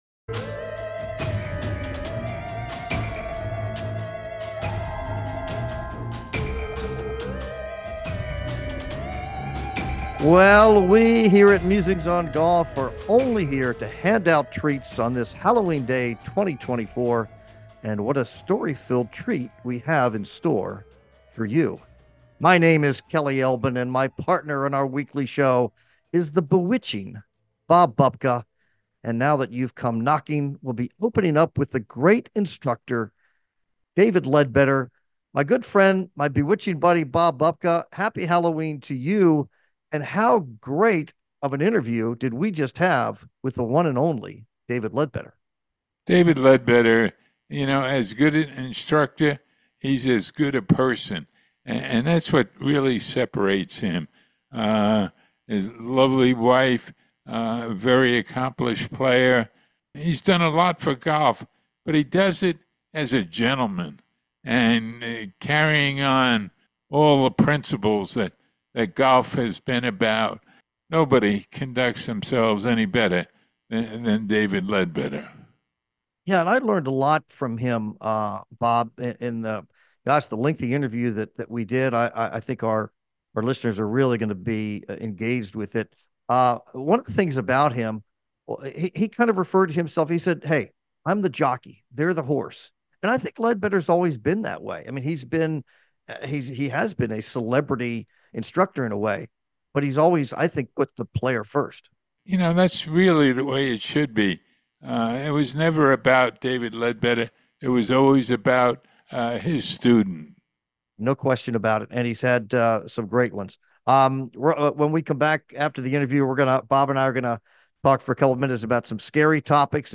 Yet, the full tale of Leadbetter lies in his passion for getting to know his players and helping them maximize their abilities. What an instructor and what an interview!